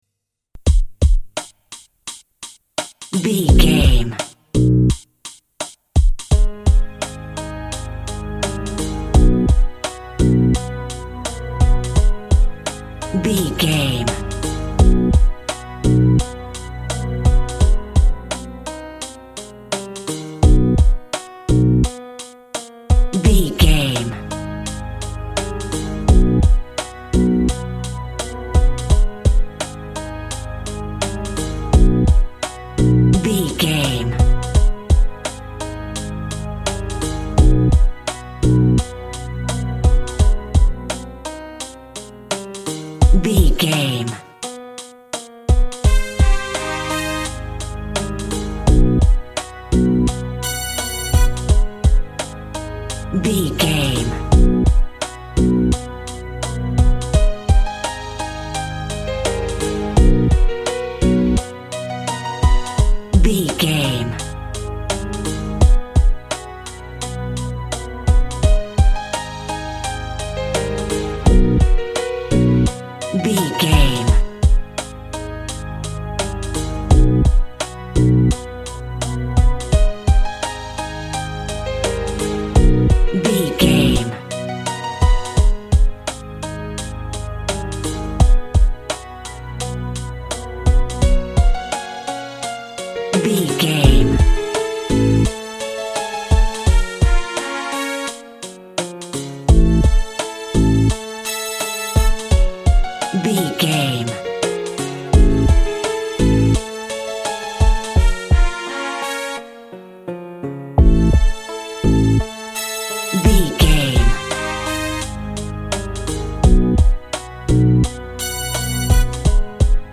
Ionian/Major
synth lead
synth bass
hip hop synths